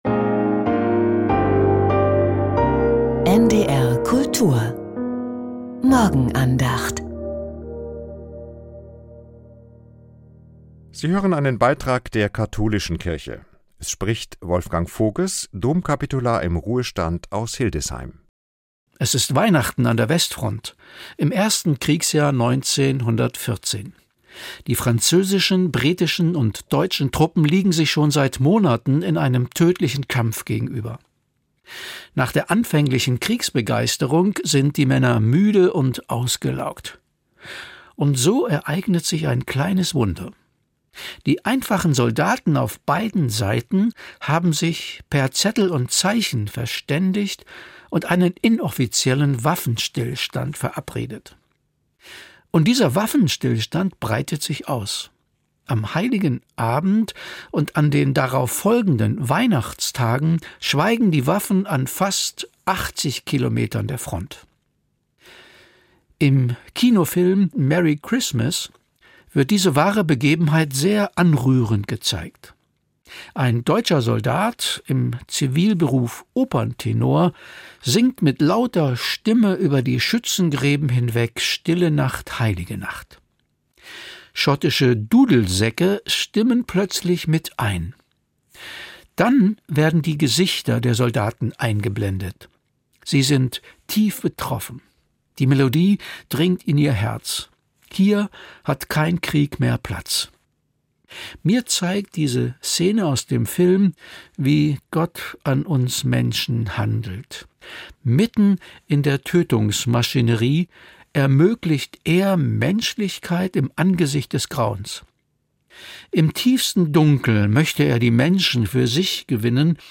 Die Morgenandacht von Domkapitular